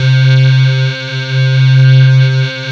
MELLOW C3.wav